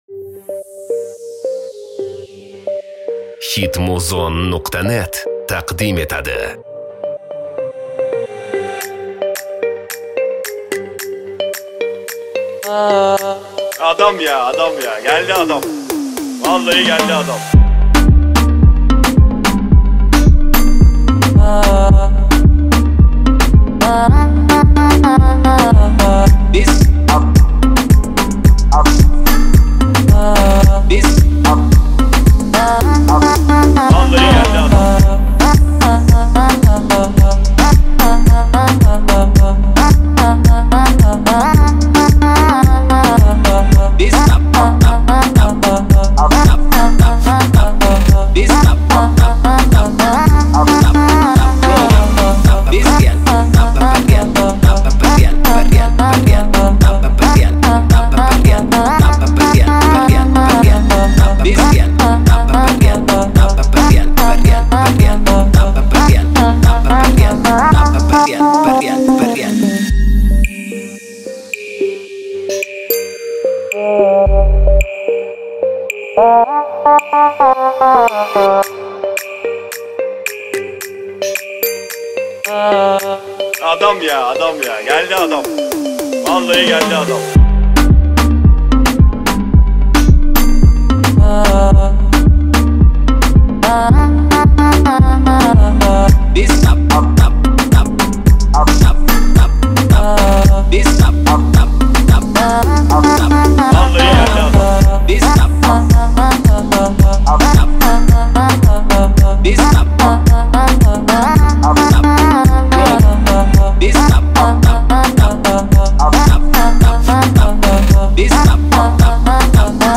Bass music
Клубная музыка бас